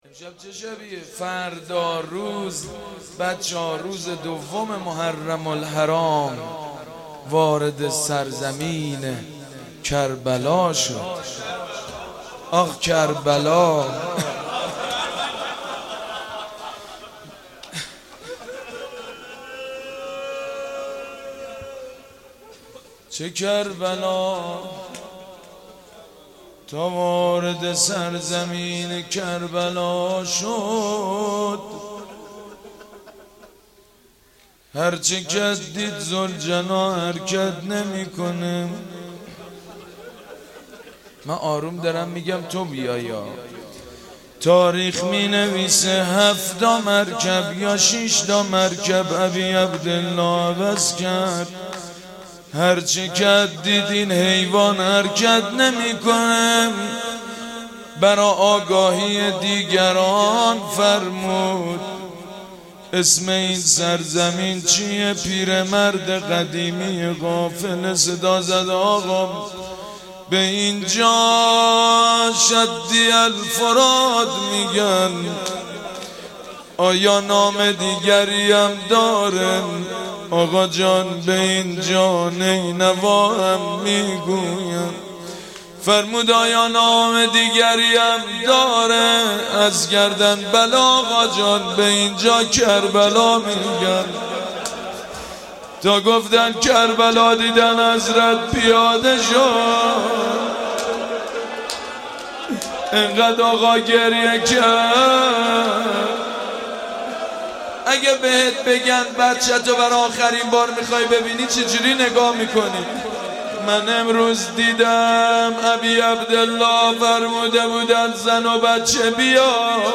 وارث: روضه ی ورودی کربلا...